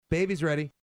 Category: Sound FX   Right: Personal
Tags: humor funny sound effects sound bites radio